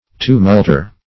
Search Result for " tumulter" : The Collaborative International Dictionary of English v.0.48: Tumulter \Tu"mult*er\, n. A maker of tumults.